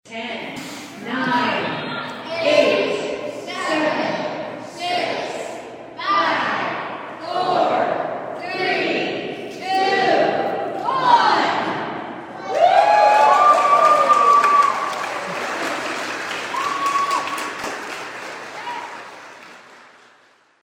The Christmas at the Capitol holiday display in Pierre kicked off with the Grand Tree Lightning Ceremony in the Capitol Rotunda last night (Tues.).